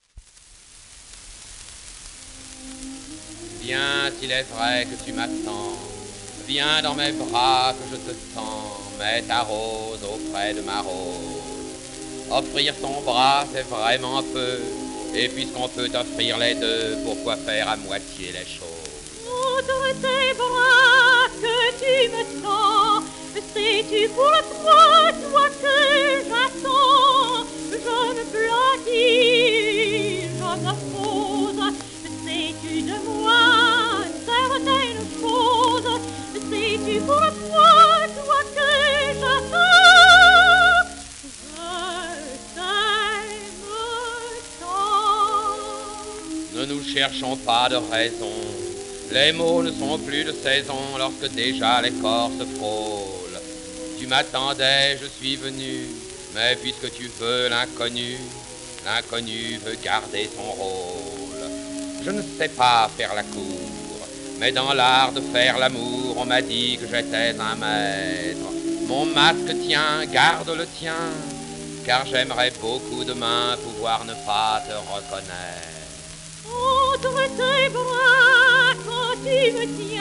オーケストラ
盤質B+ *ヒートマーク,一部面擦れ,小キズ